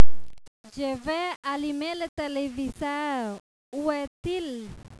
(le dialogue)